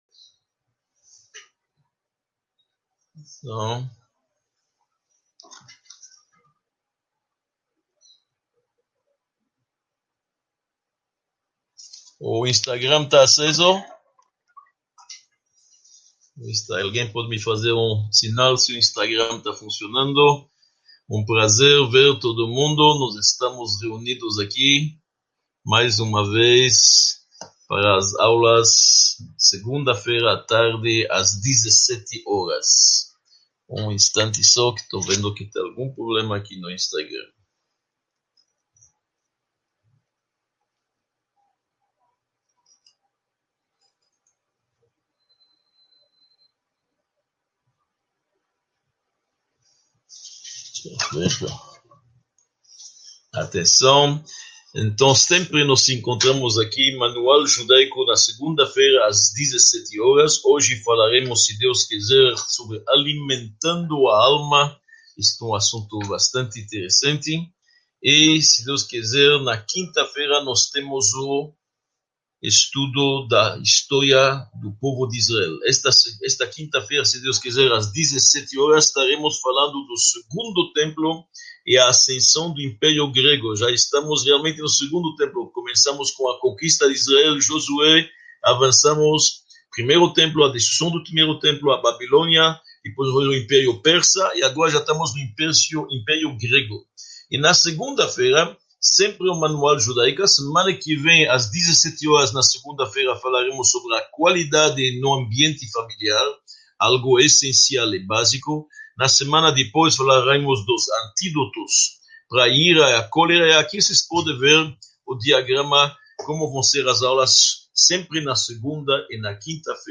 14 – Alimento para a alma: vencer as lutas internas | Módulo I – Aula 14 | Manual Judaico